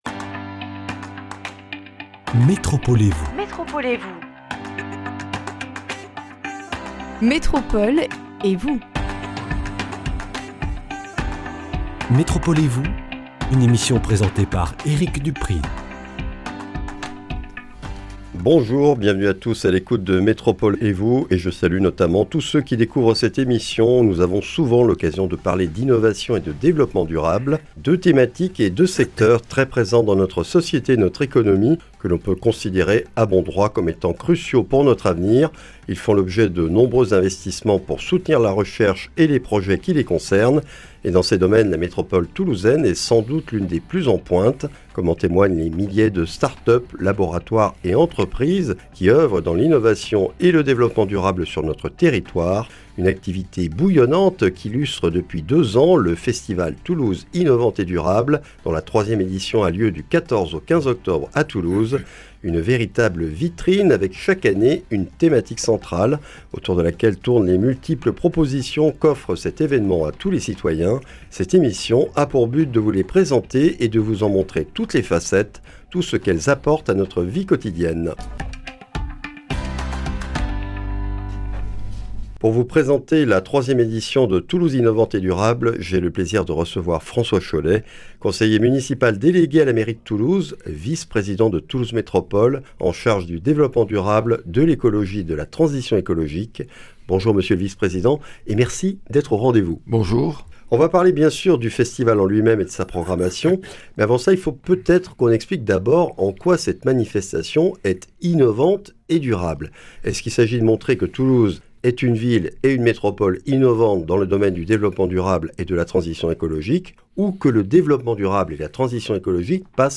L’édition 2023 nous est présenté par François Chollet, conseiller municipal délégué à la mairie de Toulouse, vice-président de Toulouse Métropole chargé de l’Écologie, du Développement durable et de la Transition énergétique.